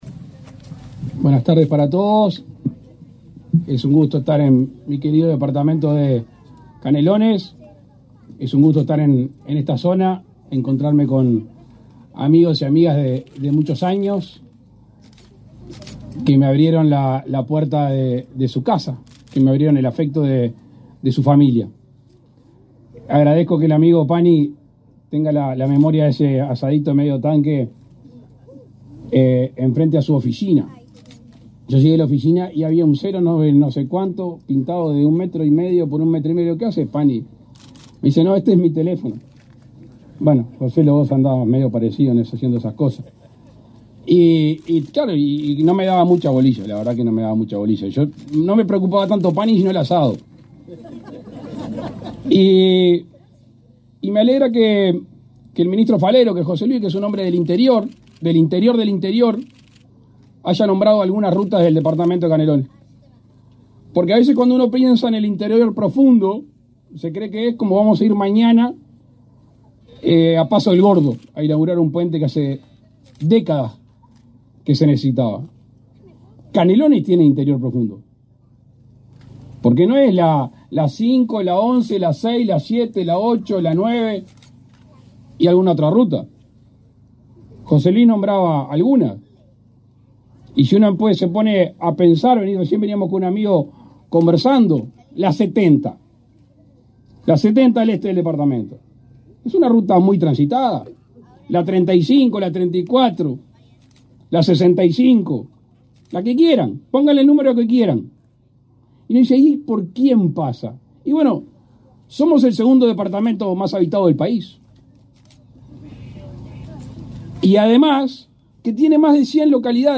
Palabras del presidente de la República, Luis Lacalle Pou
Palabras del presidente de la República, Luis Lacalle Pou 25/04/2024 Compartir Facebook X Copiar enlace WhatsApp LinkedIn El presidente de la República, Luis Lacalle Pou, participó, este 25 de abril, en la inauguración de obras viales en tramos de la ruta 81, en la localidad de Paso de la Cadena, en el municipio de San Antonio, en el departamento de Canelones.